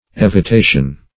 Evitation \Ev`i*ta"tion\, n.